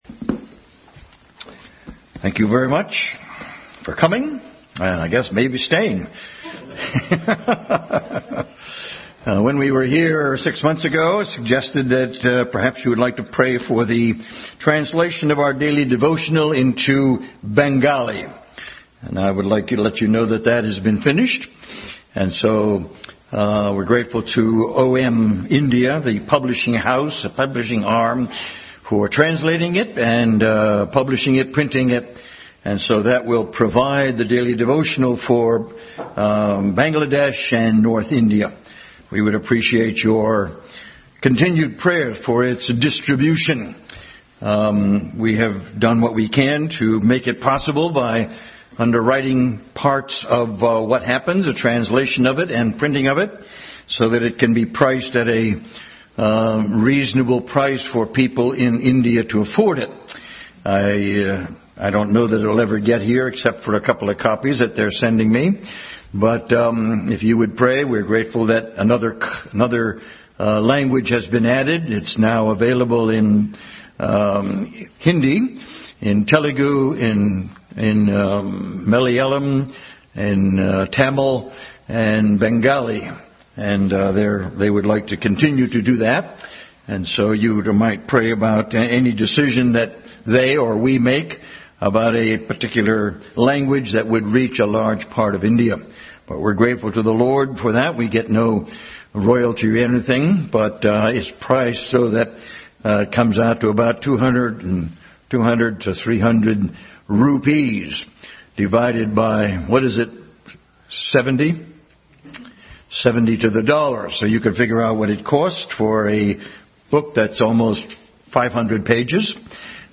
Philemon 1-25 Service Type: Family Bible Hour Bible Text